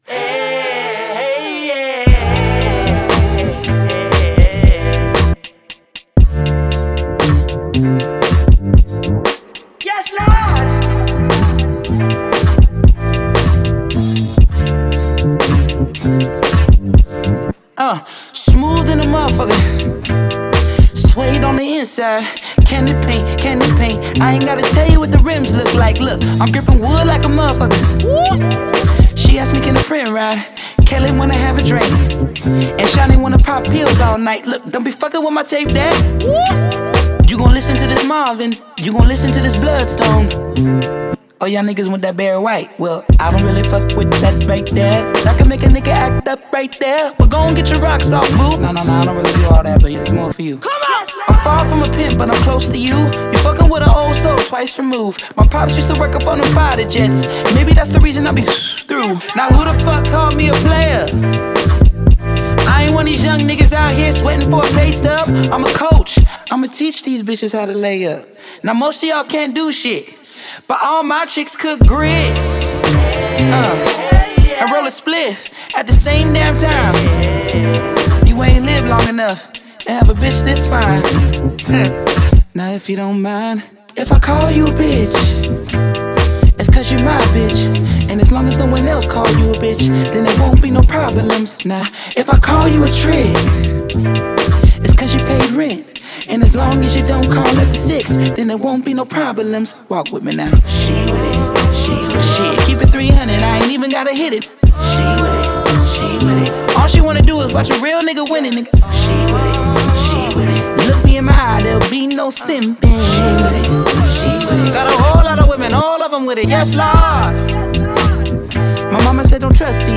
The album has also been categorized as Neo-Soul and Hip-Hop
A good example of the type of Neo-Soul vibe and sample beats